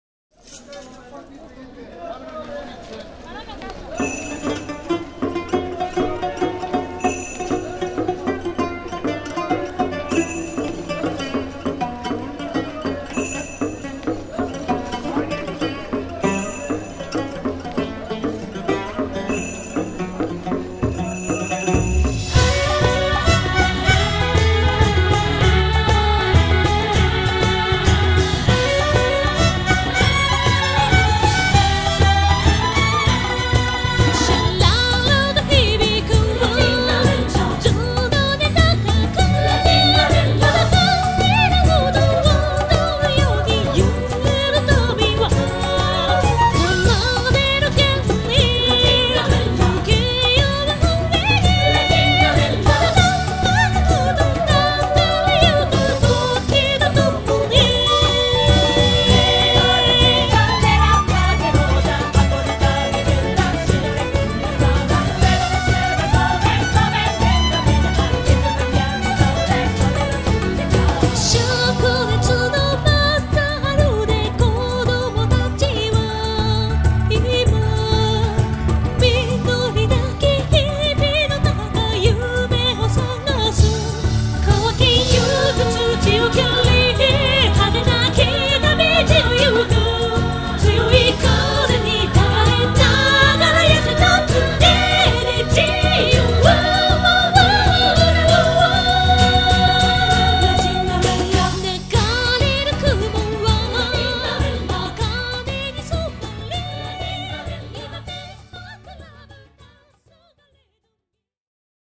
オリジナル歌曲CD
Acoustic Guitar
Percussion & Drums
Violins
Accordion
Acoustic guitar ＆ Electric guitar